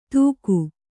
♪ tūku